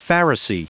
Prononciation du mot pharisee en anglais (fichier audio)
Prononciation du mot : pharisee